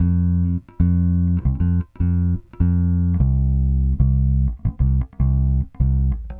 Weathered Bass 06.wav